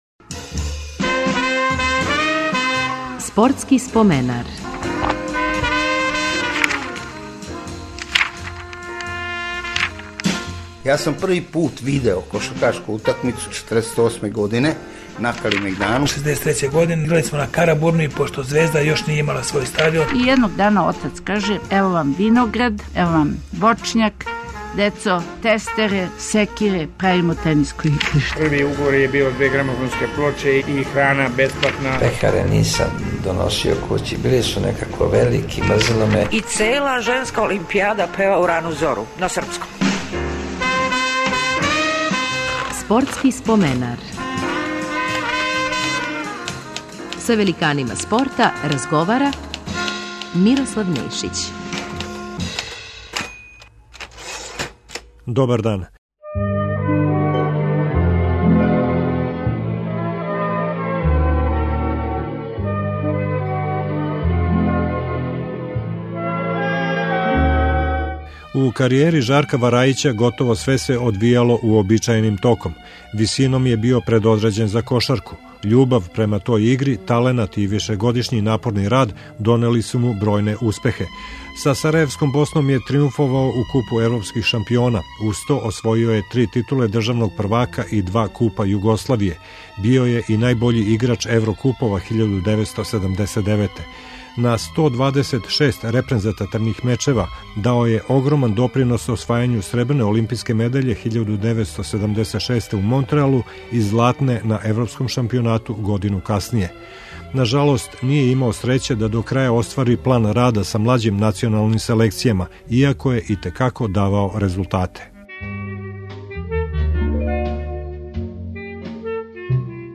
Гост ће бити кошаркаш Жарко Варајић.